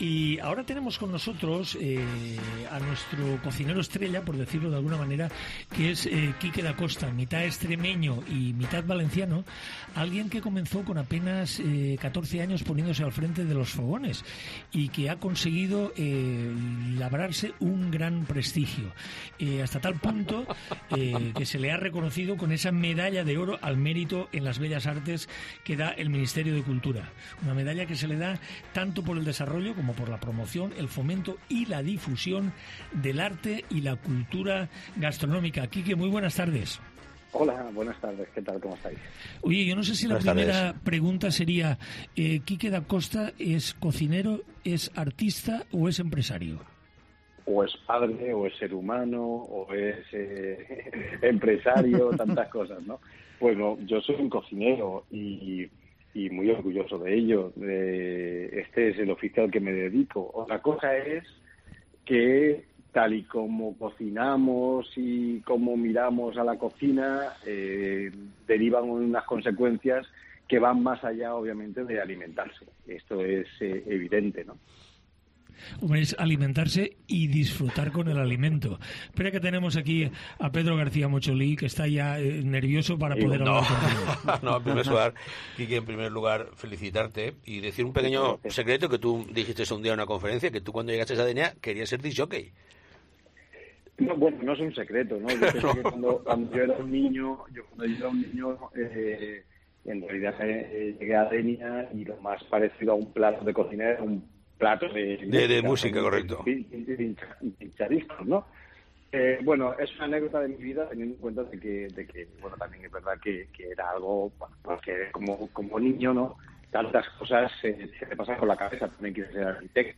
GastroCOPE | Entrevista a Quique Dacosta